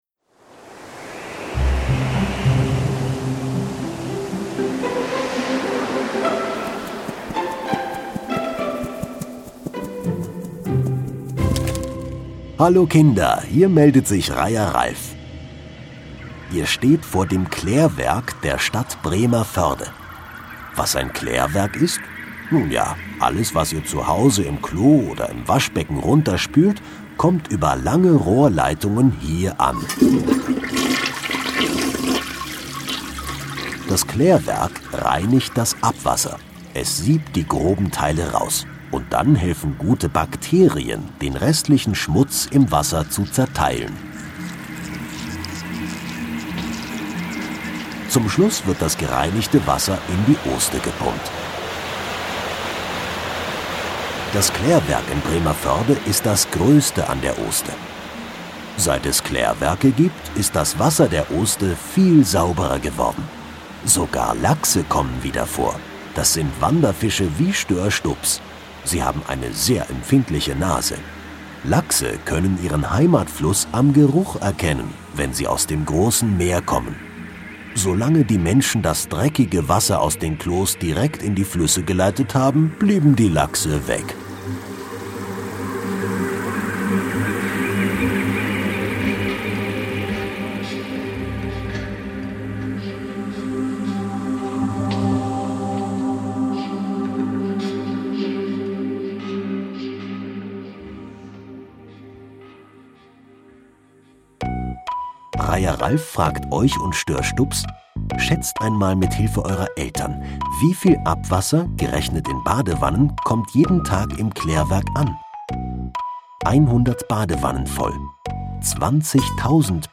Klärwerk Bremervörde - Kinder-Audio-Guide Oste-Natur-Navi